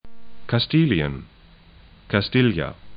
Kastilien kas'ti:lĭən Castilla kas'tɪlja es Gebiet / region 41°00'N, 03°26'W